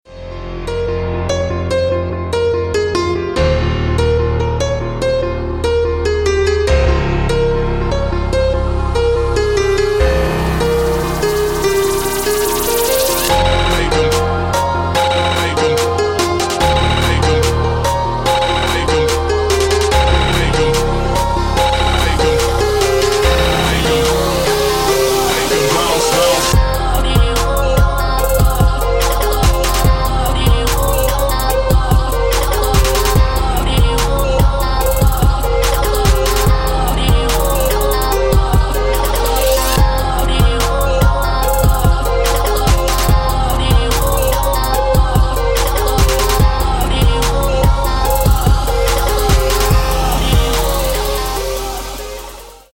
• Качество: 160, Stereo
dance
Electronic
EDM
Trap
club
пианино
future bass